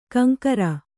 ♪ kaŋkara